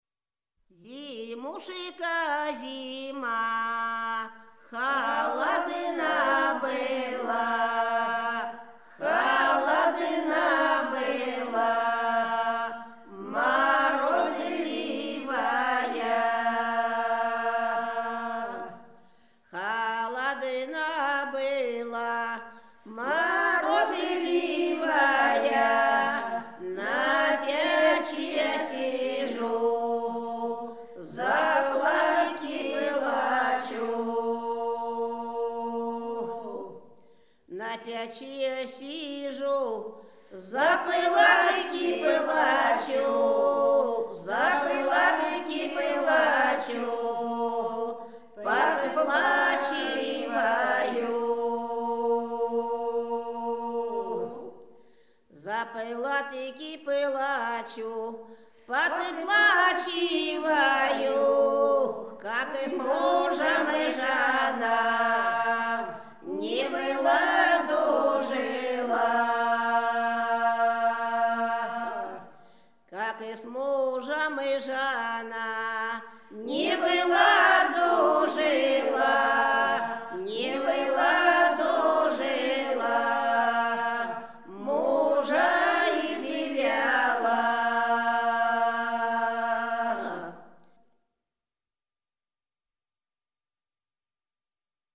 Рязань Секирино «Зимушка зима», лирическая.